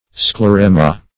Sclerema \Scle*re"ma\, n. [NL., fr. Gr. sklhro`s hard.] (Med.)